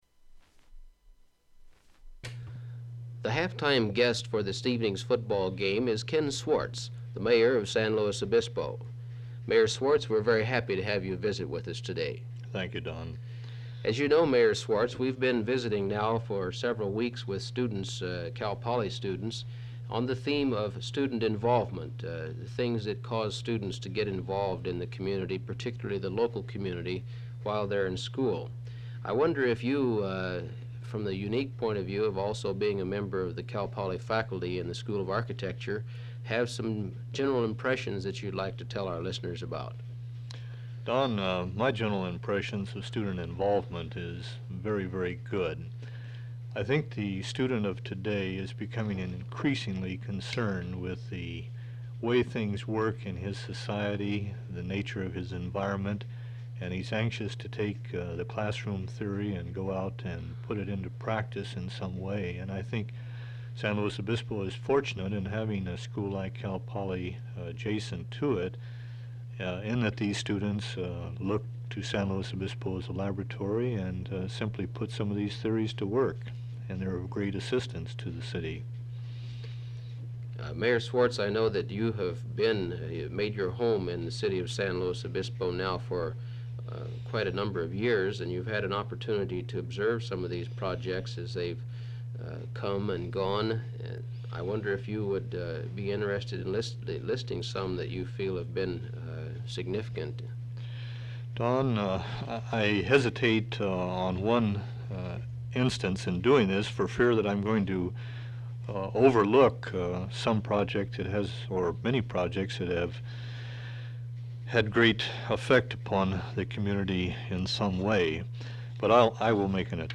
Interview with San Luis Obispo mayor, Ken Schwartz, during half-time of a Cal Poly football game. [00:00:01] Interview with Mayor, Ken Swartz, begins [00:00:28] Interviewer asks the mayor his impressions on student involvement in the community [00:01:30] Interviewer asks mayor about projects that have had a large impact on the SLO community [00:01:40] Mayor talks about collections for feeding the needy, storm assistance, political clubs on campus, mission projects, and plenty of other projects [00:03:20] Interviewer asks if mayor thinks that the community needs a special type of attitude to realize the benefits of being a college community [00:03:40] Mayor talks about physical projects that have benefited the community, bridges, statues, creek cleanings.
• Open reel audiotape